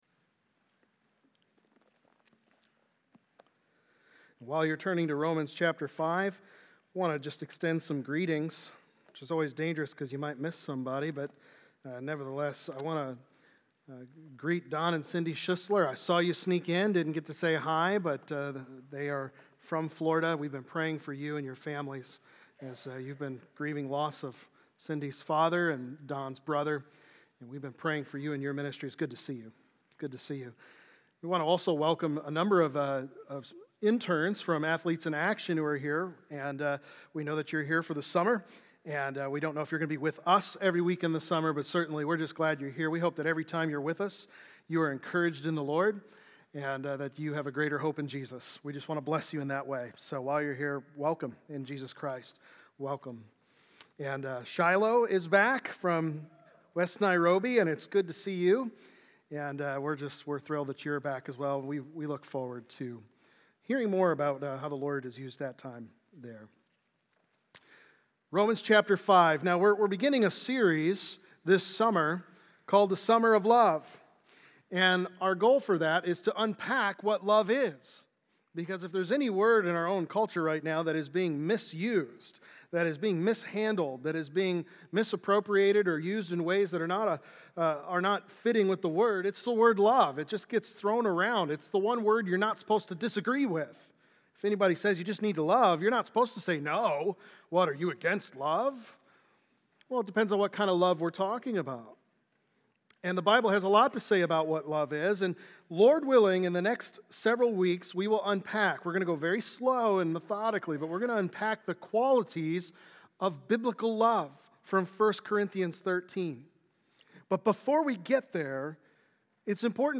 God’s Love Demonstrated | Baptist Church in Jamestown, Ohio, dedicated to a spirit of unity, prayer, and spiritual growth